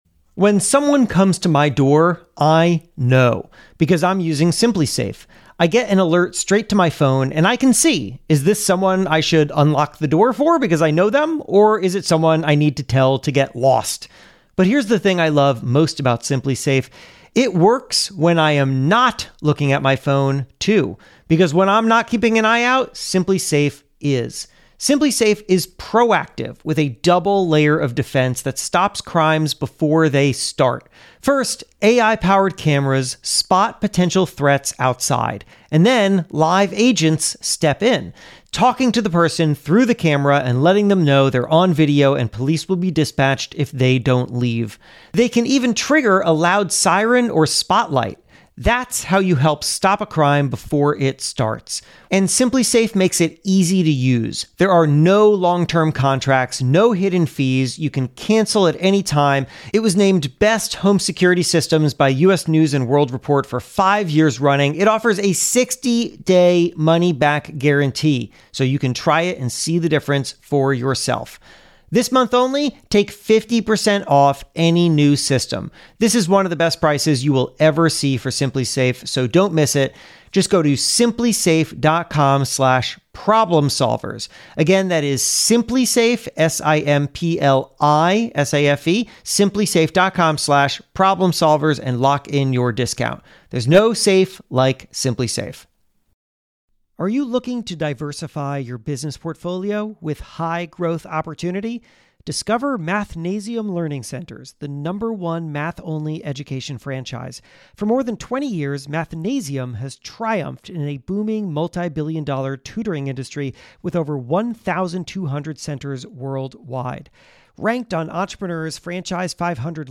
In this candid conversation, Robert breaks down the real questions investors should ask, and the common mistakes founders keep making. He also warns about the wrong lesson some people take from Shark Tank.